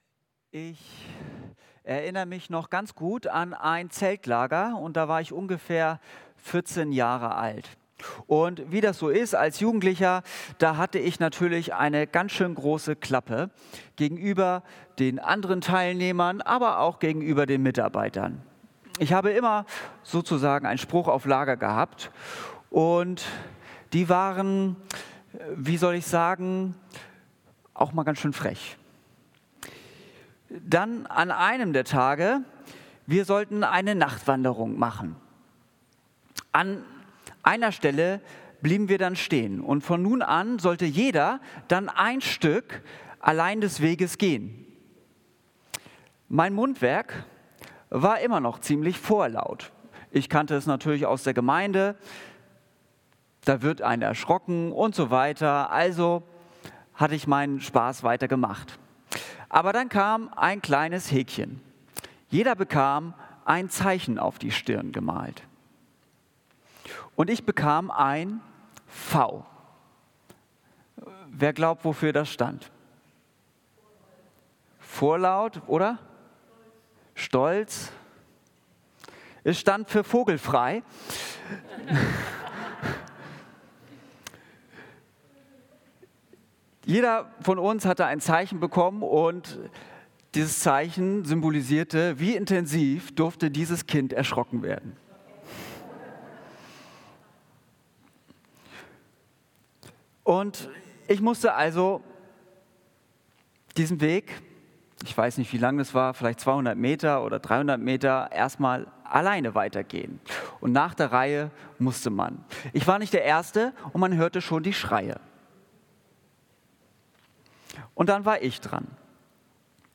Gottesdienst
Predigt